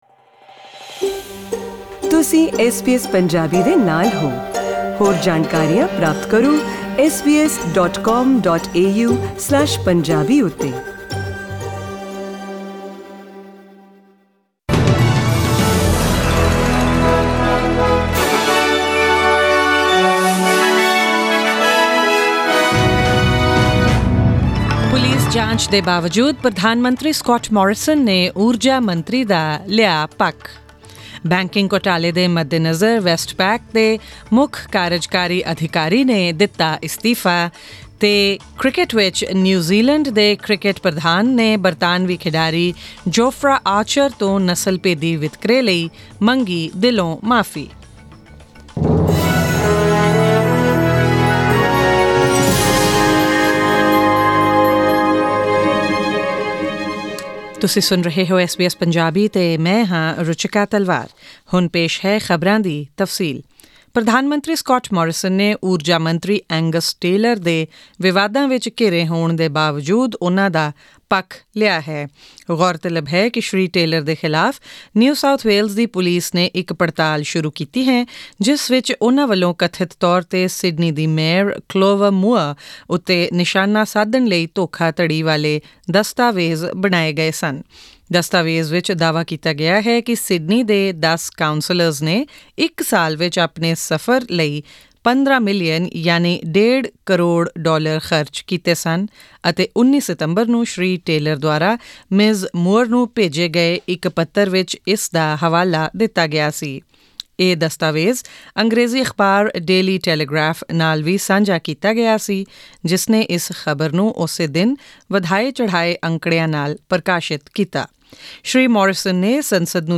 SBS Punjabi News: November 26, 2019